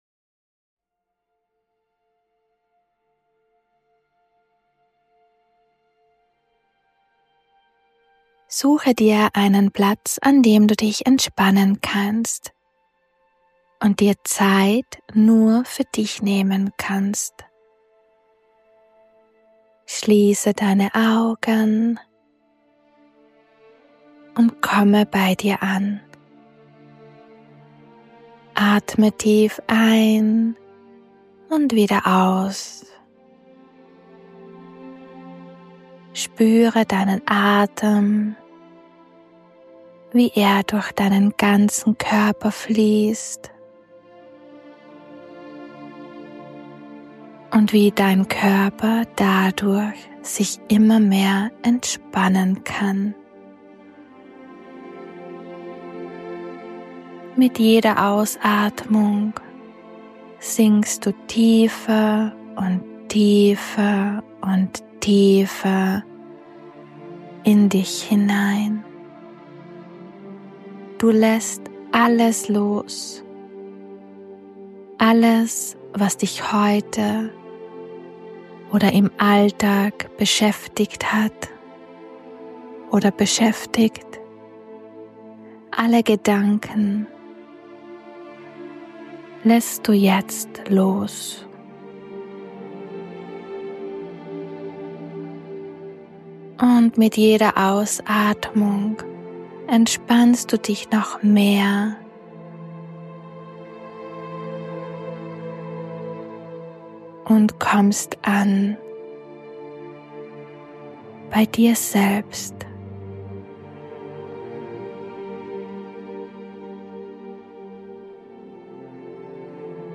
Diese Meditation ist eine Akasha Energetik Healing Meditation, in dieser ich zusätzlich Energetik Healing für das Kollektiv angewendet habe.